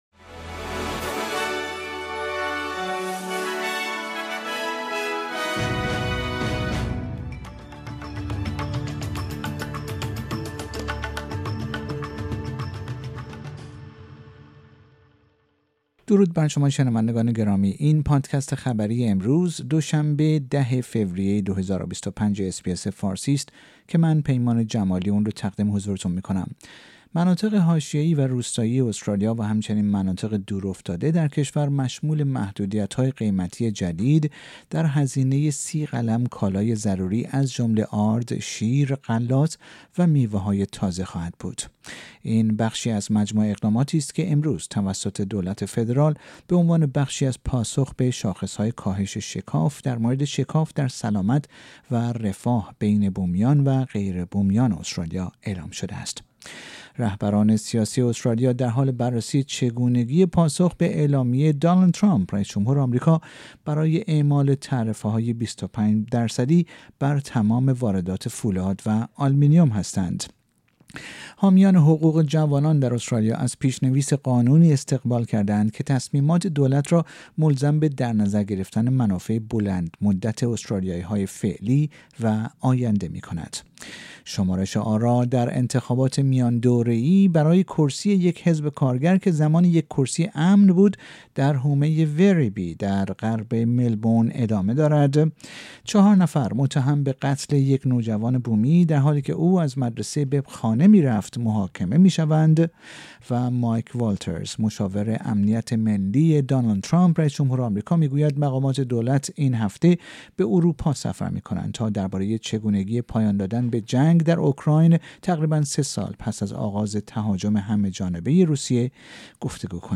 در این پادکست خبری مهمترین اخبار استرالیا در روز دوشنبه ۱۰ فوریه ۲۰۲۵ ارائه شده است.